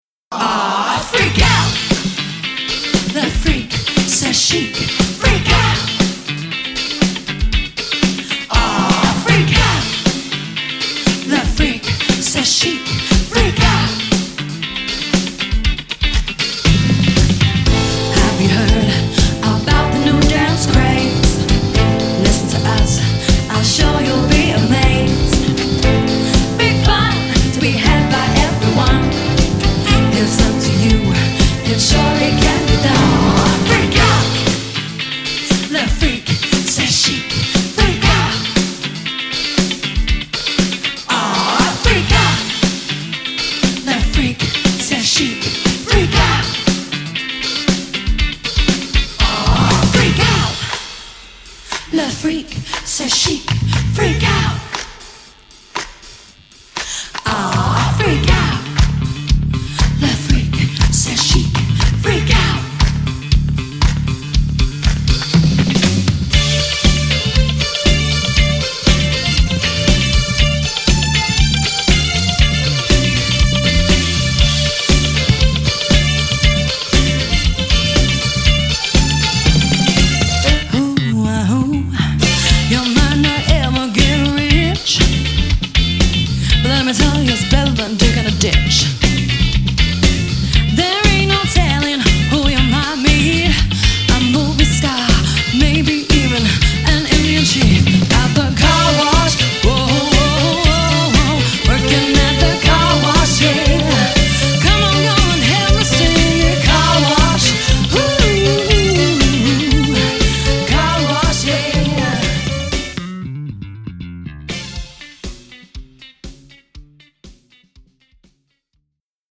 • Option to add trumpet/trombone to the 6-piece line-up